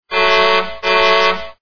truckhorn.mp3